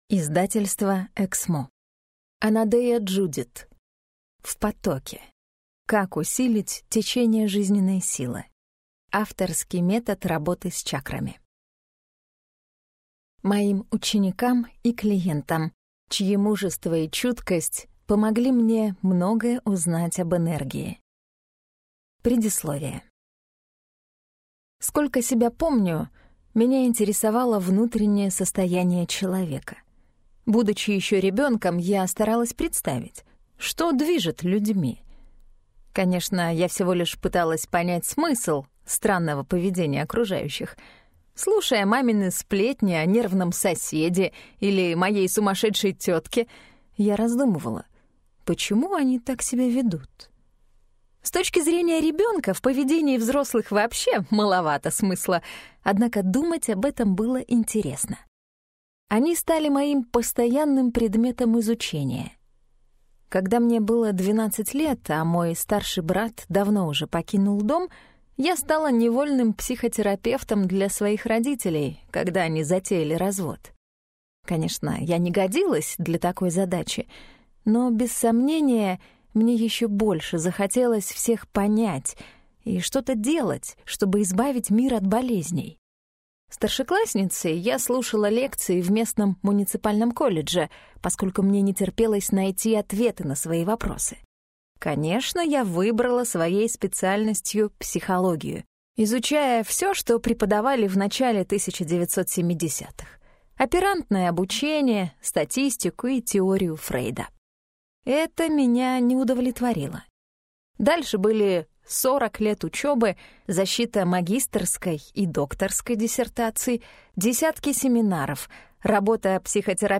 Аудиокнига В потоке. Как усилить течение жизненной силы: авторский метод работы с чакрами | Библиотека аудиокниг